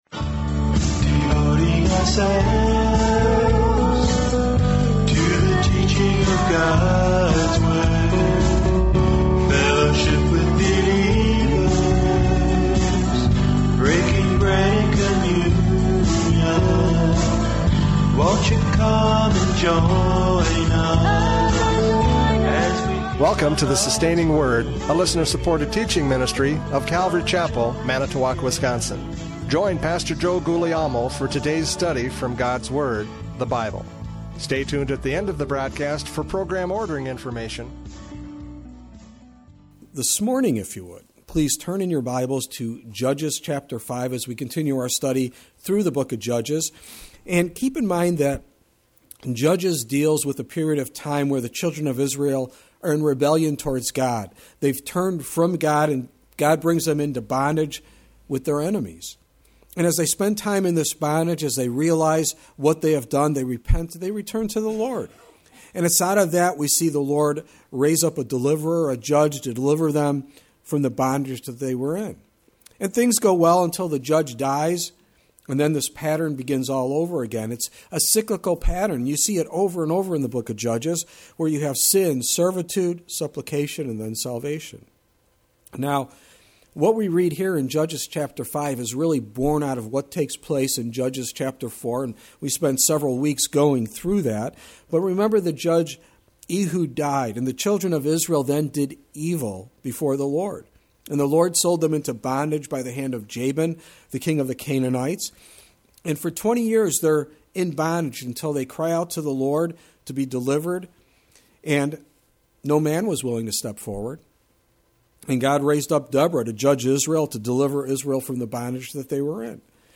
Judges 5:6-8 Service Type: Radio Programs « Judges 5:3-5 Remember God!